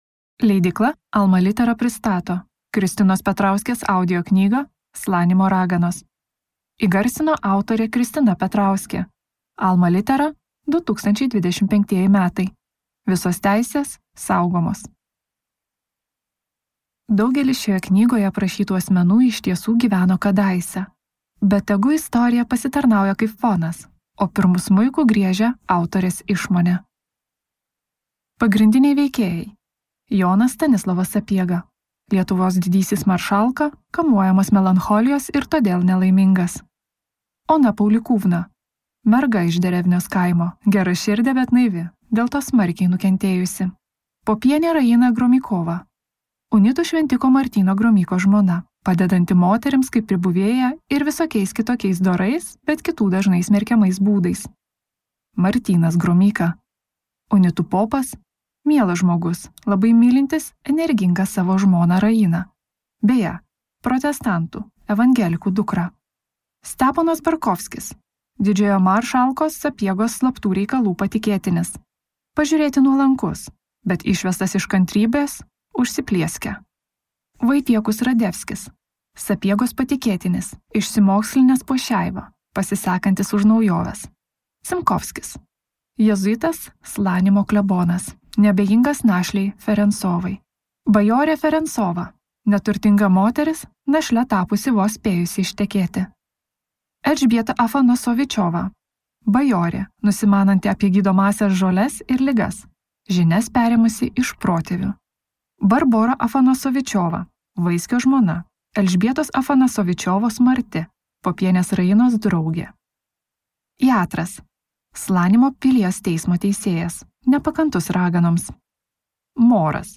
Slanimo raganos | Audioknygos | baltos lankos